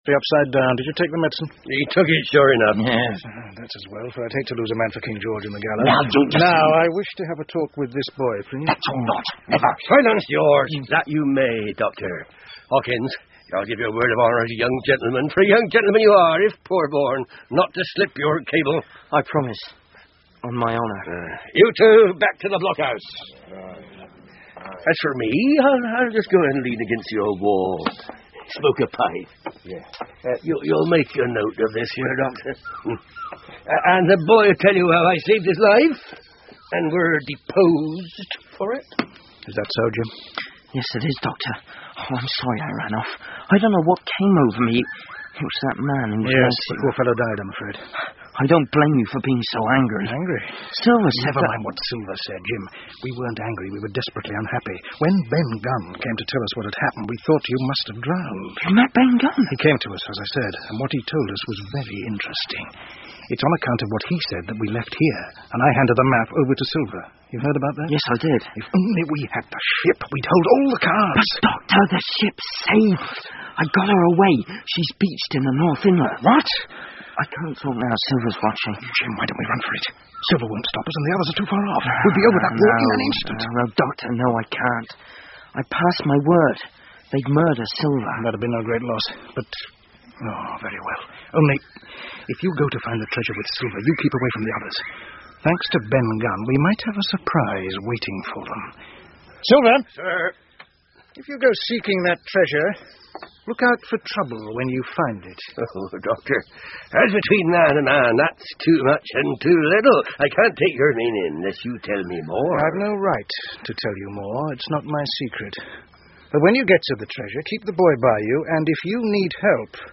金银岛 TREASURE ISLAND 儿童英文广播剧 16 听力文件下载—在线英语听力室